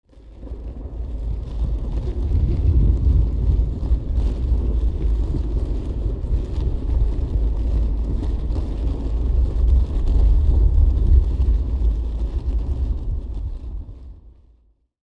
328d67128d Divergent / mods / Soundscape Overhaul / gamedata / sounds / ambient / soundscape / underground / under_2.ogg 398 KiB (Stored with Git LFS) Raw History